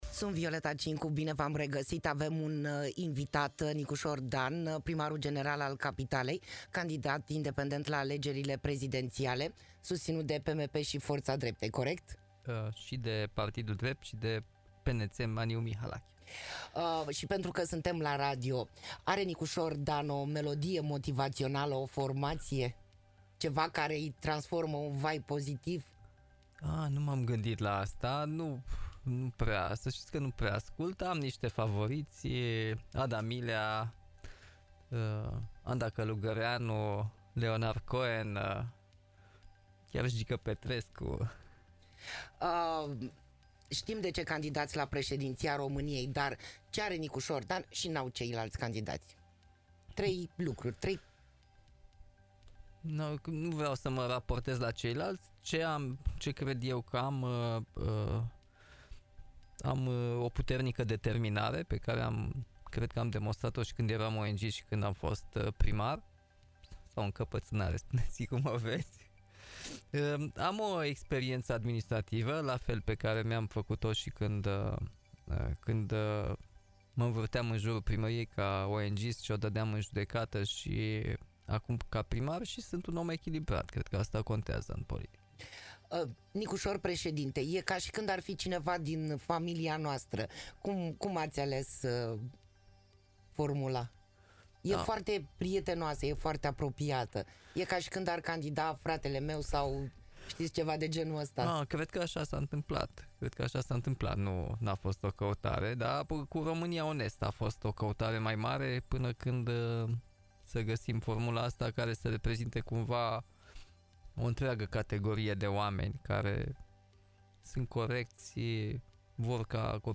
Nicușor Dan a fost prezent în emisiunea HIT Voice la Radio HIT.